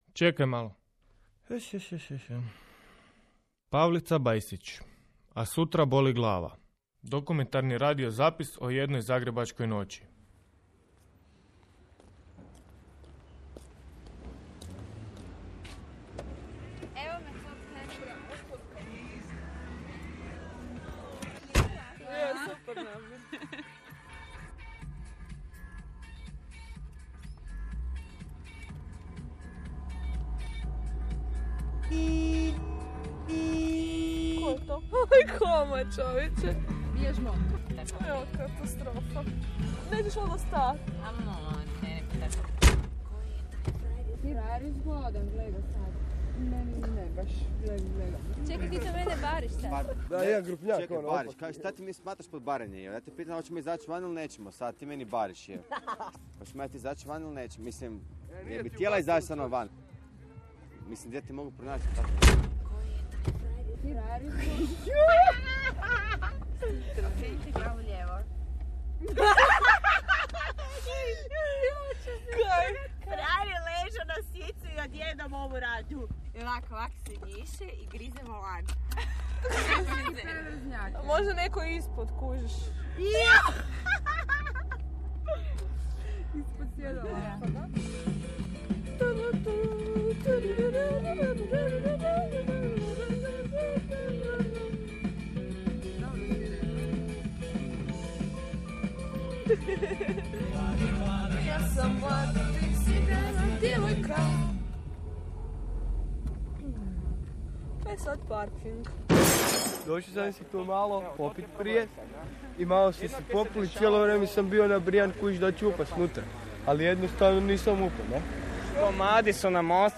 Emisija dramskoga sadržaja namijenjena mladim od 15 do 20 godina.